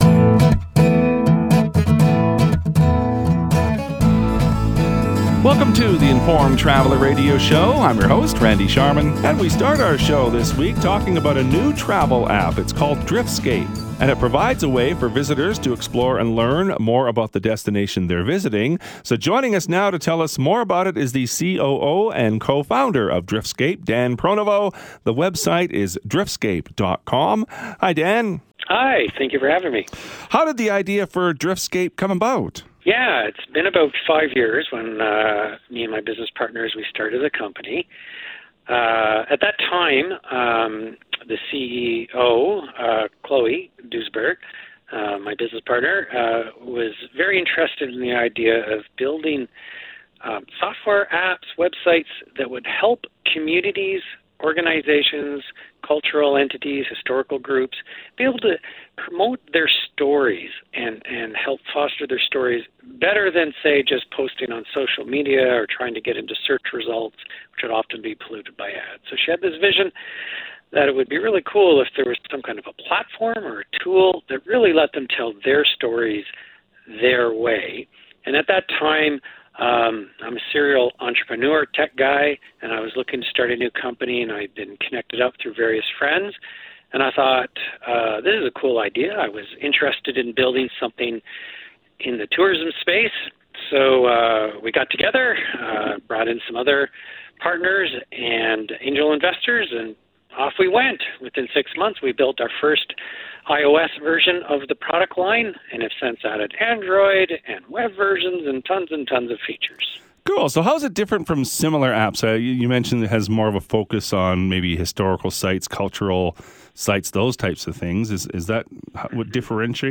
The Informed Traveler is a weekly travel program dedicated to bringing you the latest travel news and information.